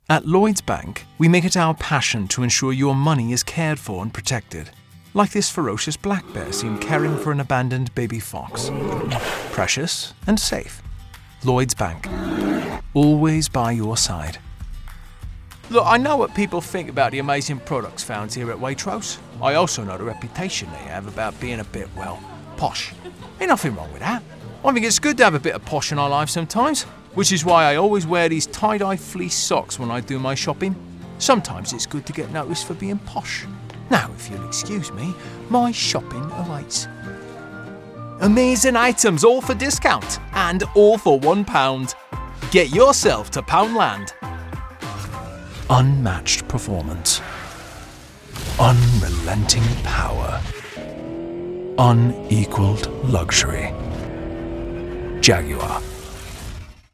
UK Commercial↓ Download
Professionally-built, broadcast quality, double-walled LA Vocal Booth.